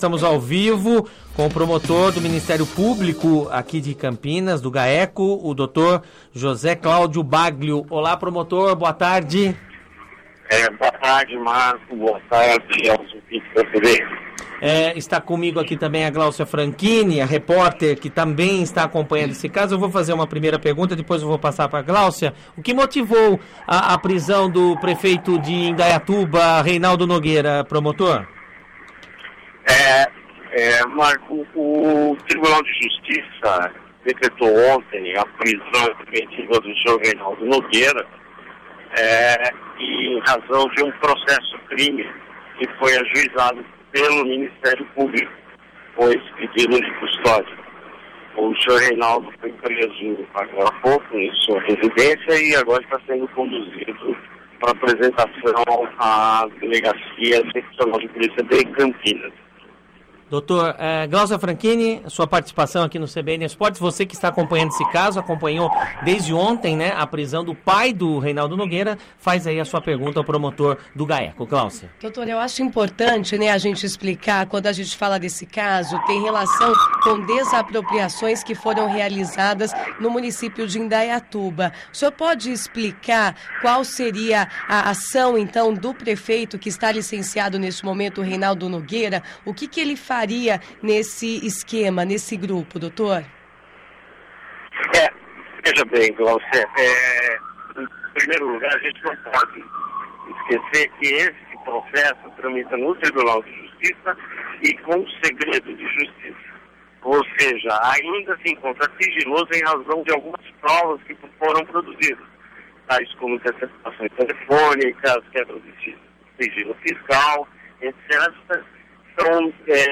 ENTREVISTA-BAGLIO.mp3